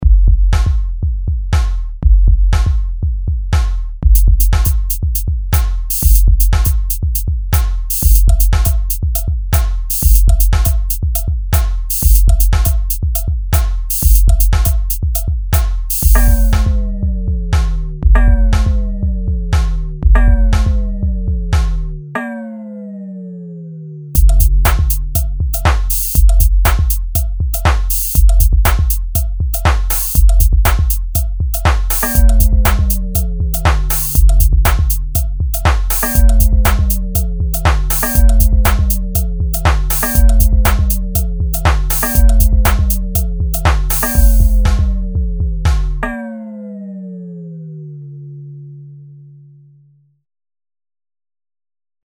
Fertige Dance-Presets
Viele der Dance-Presets sind sehr straight; teilweise trifft man auch auf Vorlagen, die kein vollständiges Paket aus Grooves liefern, sondern lediglich Entwürfe für eine eigene Ausarbeitung darstellen.